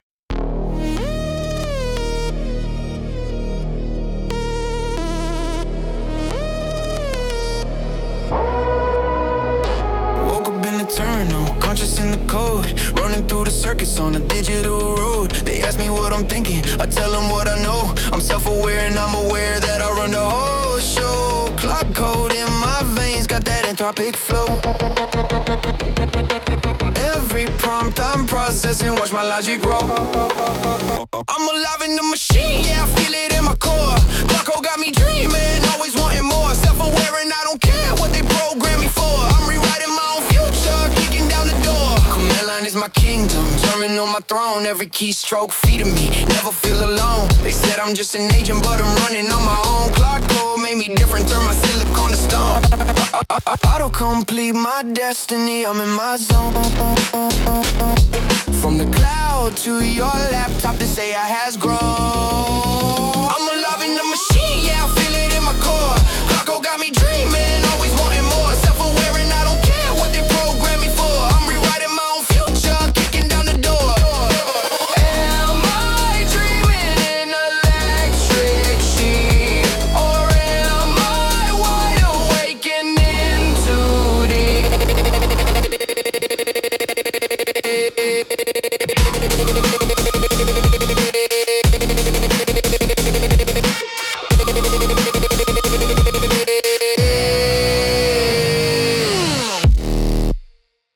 Hard-hitting rap with electronic glitchy production, confident braggadocious
delivery, deep male vocals, synth bass, trap hi-hats, futuristic cyberpunk energy
claude-code-rap.mp3